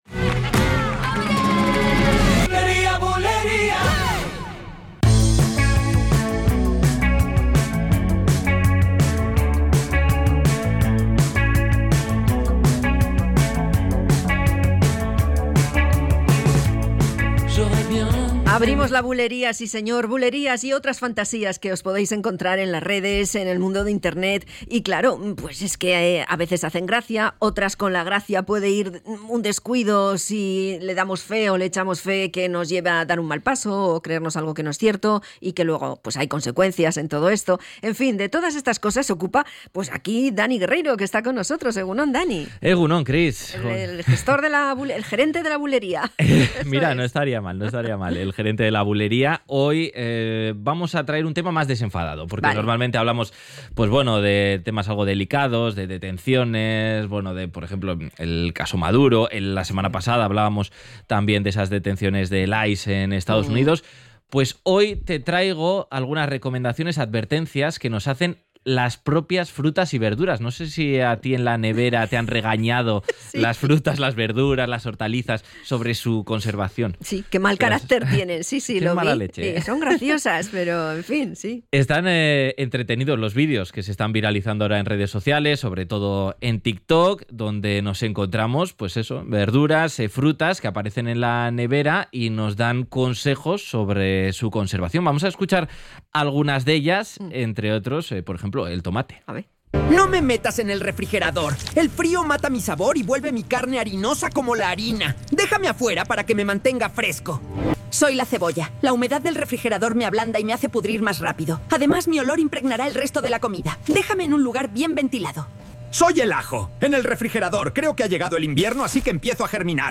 En BulerIA escuchamos algunos de estos ejemplos.